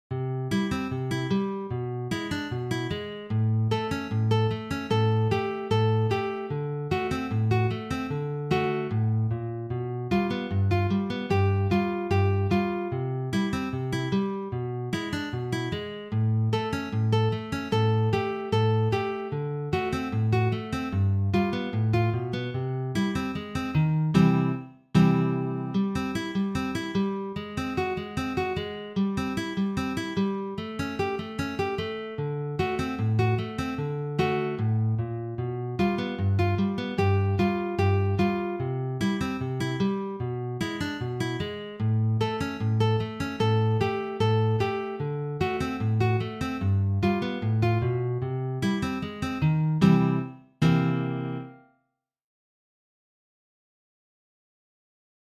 Gitarre, akustische Gitarre